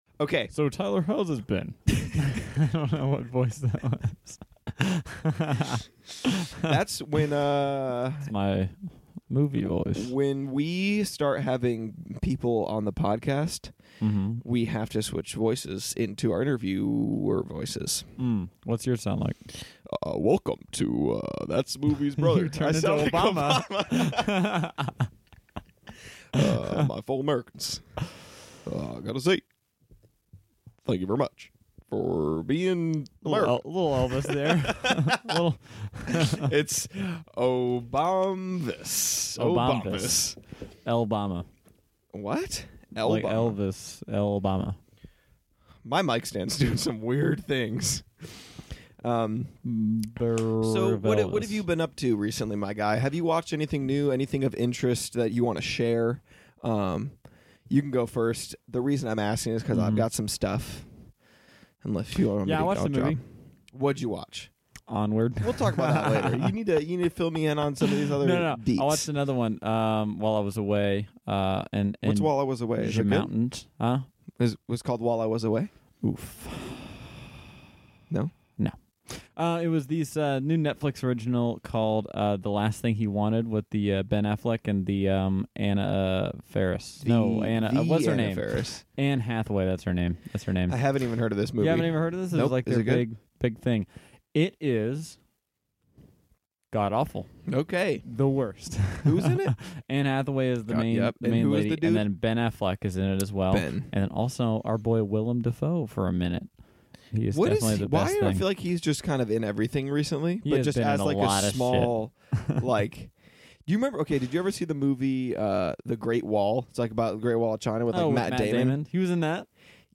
The Boys go live on Instagram